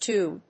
toom.mp3